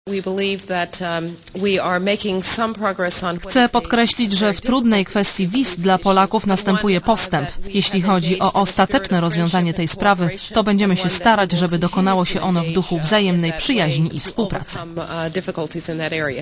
Mówi Condoleeza Rice Rice, która spotkała się też z szefem MSZ Adamem Danielem Rotfeldem, dziękowała Polsce za "nadzwyczajne" zaangażowanie oraz poświęcenie w Iraku.